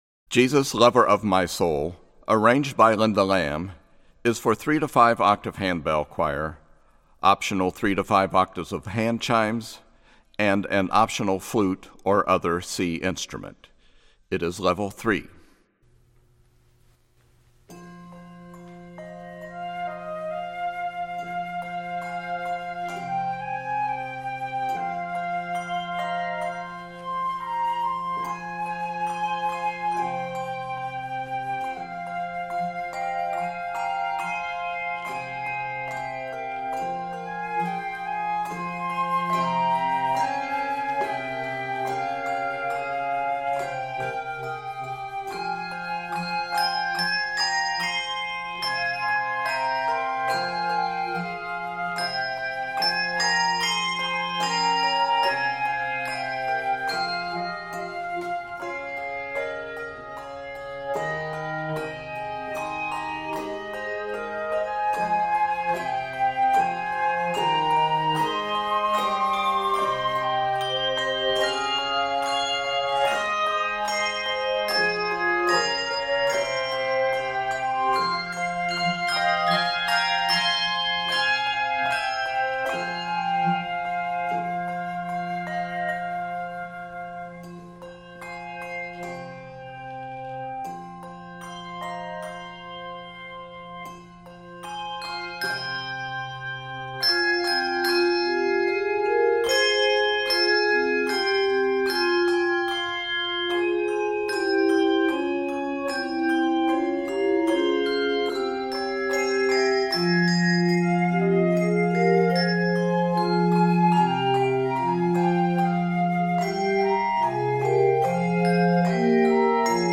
hauntingly beautiful arrangement
Welsh hymntune
Keys of f minor and g minor.